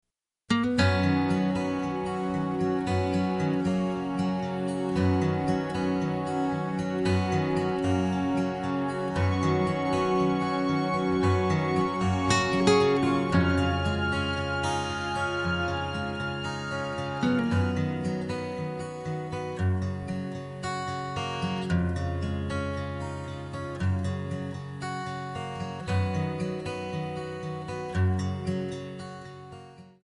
Bb
MPEG 1 Layer 3 (Stereo)
Backing track Karaoke
Country, 1990s